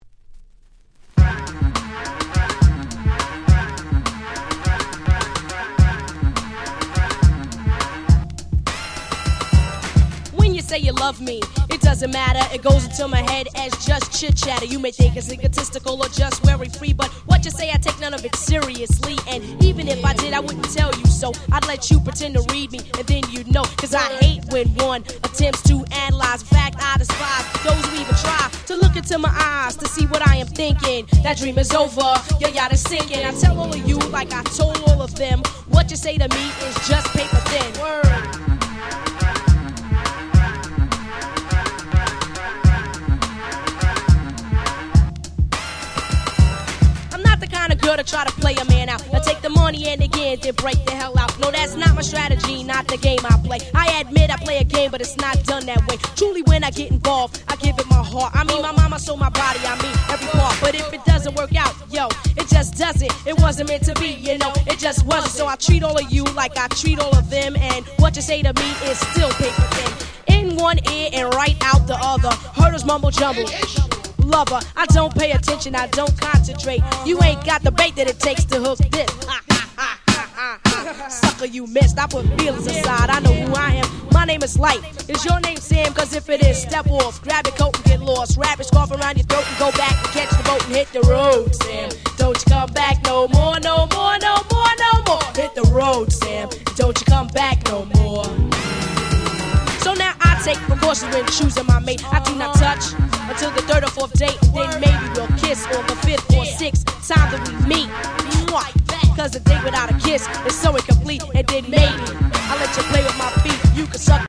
88' Hip Hop Super Classics !!